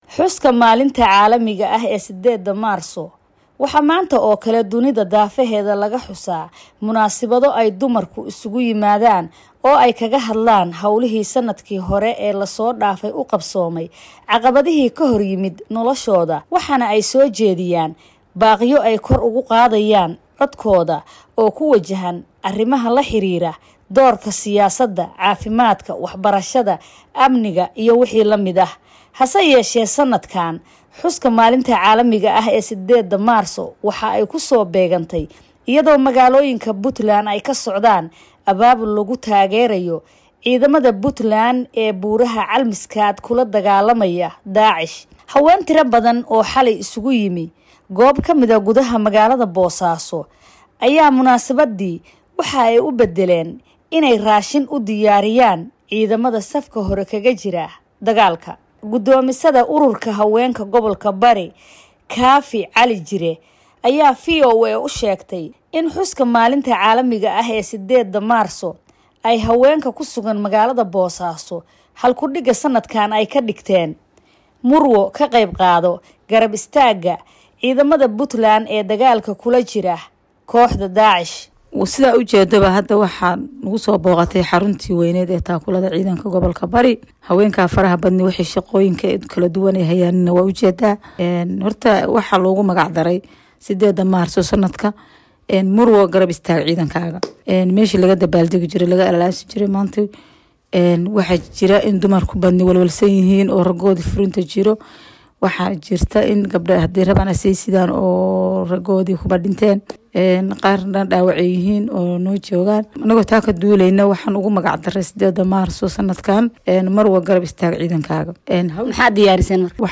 Warbixinada 8-da Maarso oo wariyeyaasheenu ka soo dieren Muqdishu, Nairobi, Ivo Boosaaso halka hoose ka dhageyso.